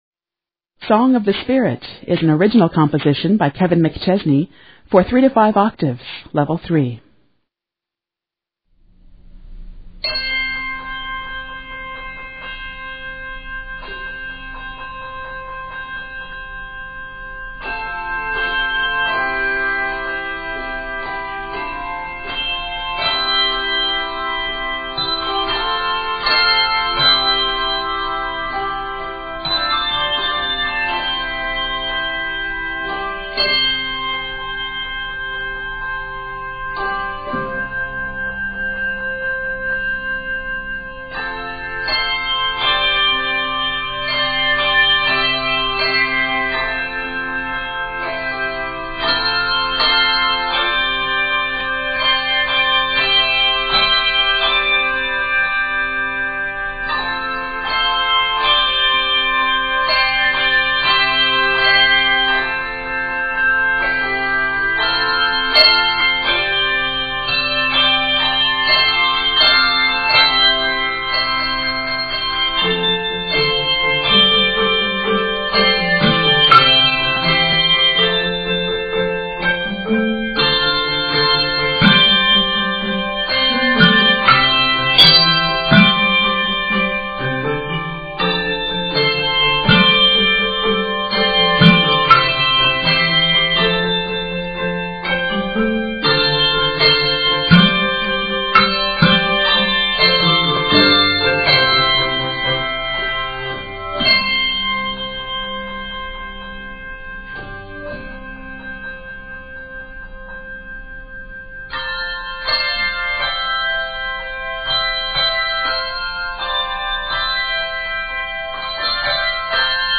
handbells